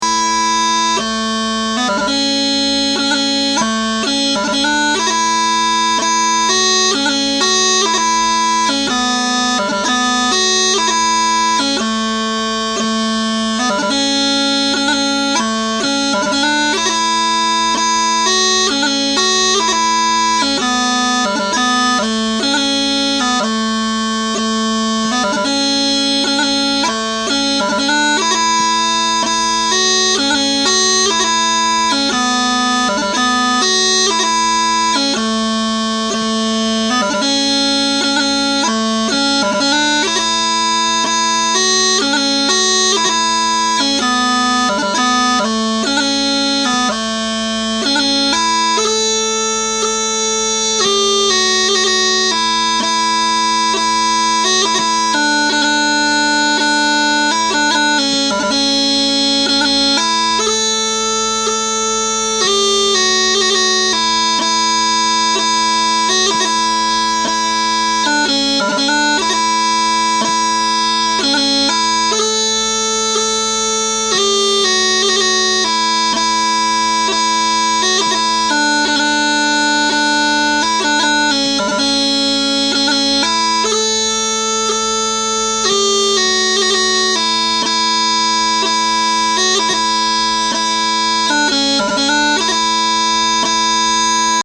Smallpipes - If you want to play along but my chanter isn't in tune with yours, check out Audacity, which allows you to change the pitch of the audio without changing the speed.
MP3 (played slow)